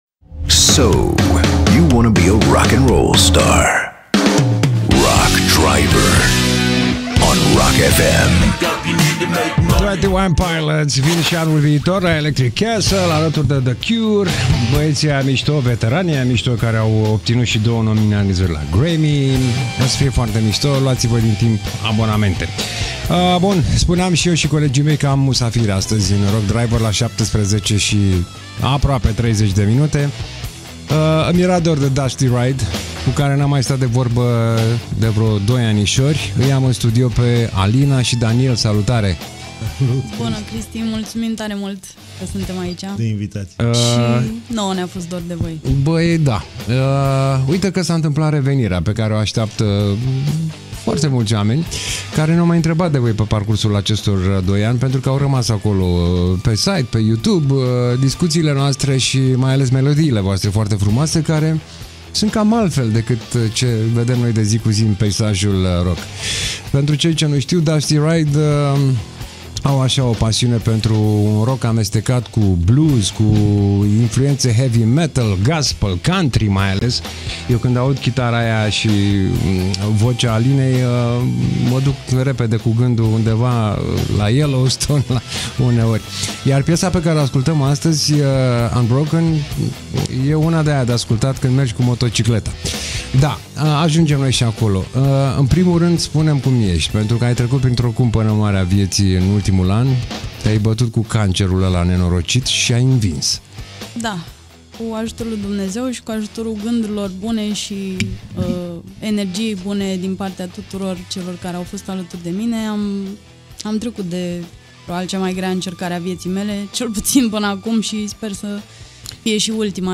Rock Driver - 11.11.2025 - invitați Dusty Ride, despre revenirea pe scenă și primele apariții live